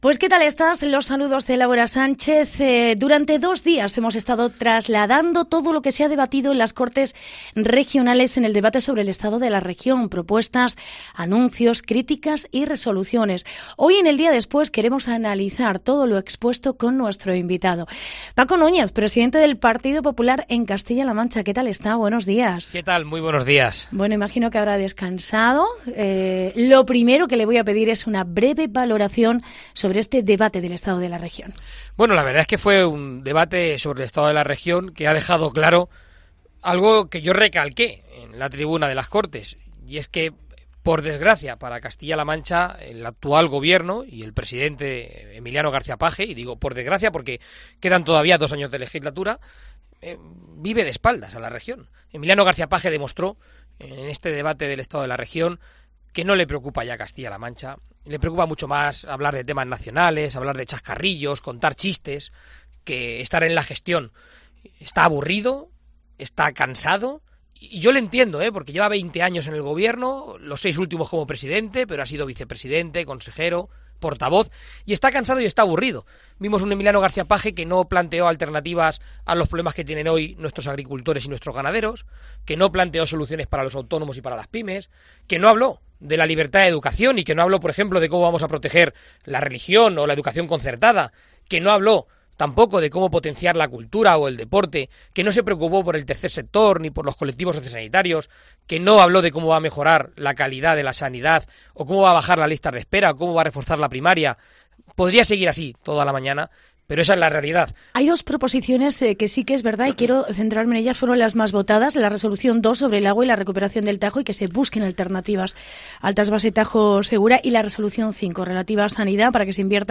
Entrevista Paco Núñez en COPE CLM